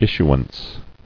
[is·su·ance]